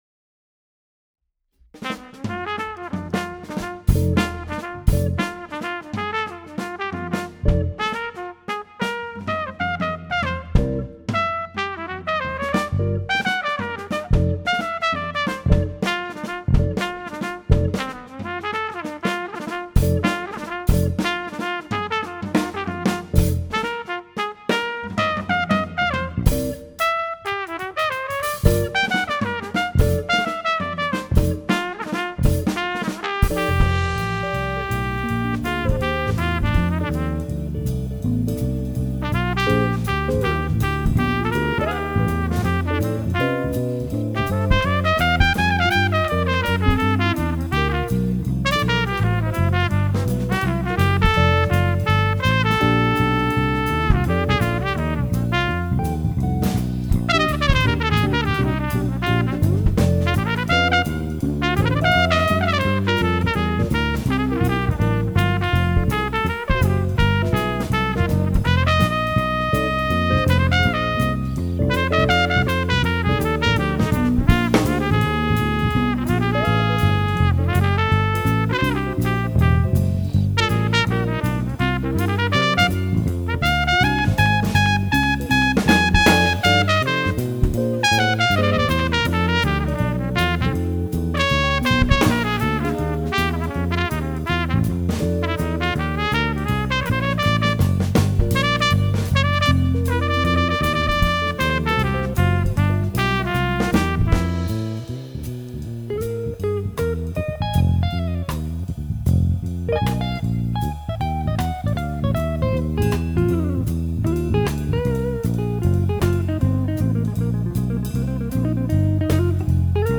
jazz tune